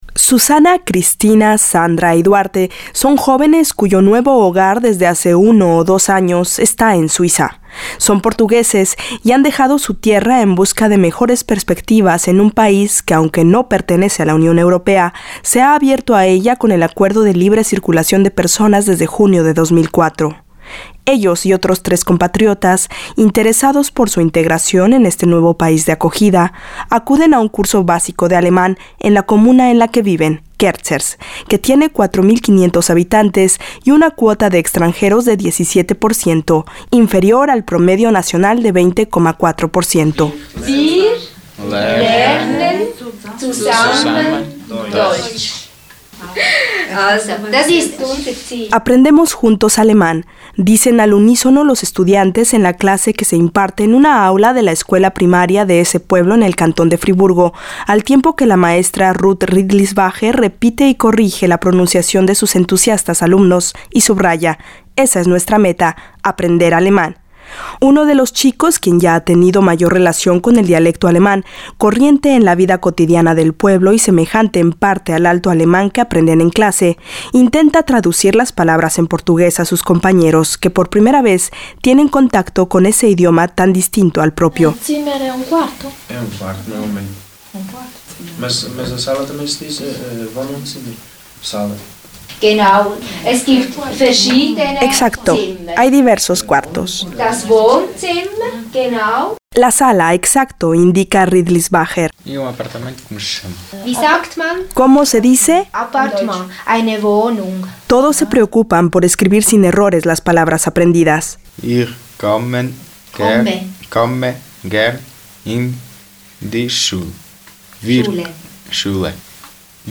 En un curso de integración y aprendizaje del alemán para principiantes en Kerzers, un pueblo de Suiza predominantemente agrícola, los estudiantes provienen todos de Portugal. Esta clase forma parte de la oferta de cursos para niños en edad preescolar y adultos inmigrantes que respalda el gobierno comunal, el primer proyecto de su tipo en el Friburgo germanófono.